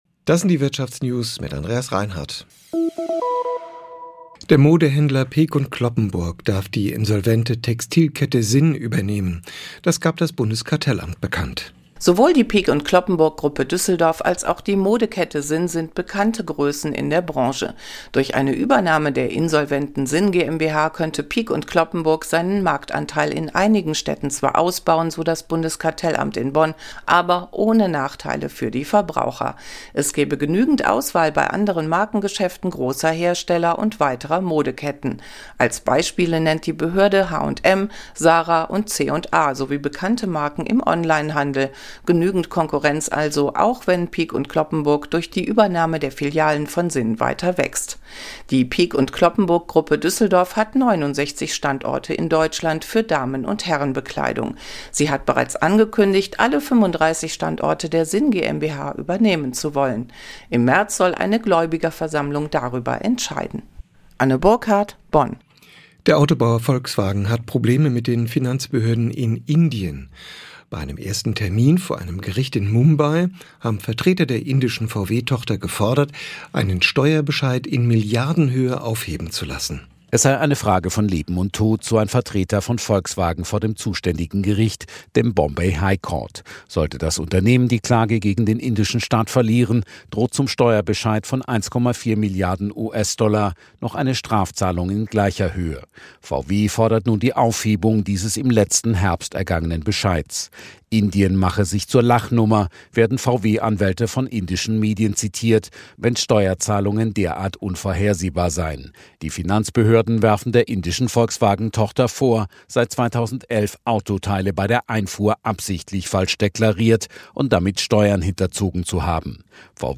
… continue reading 103 つのエピソード # Business News # Nachrichten # Südwestrundfunk # SWR Aktuell # Wirtschaft # Unternehmer # Börse # Beschäftigung # Arbeitnehmer # Arbeitgeber # Konzerne # Soziales # Gewerkschaft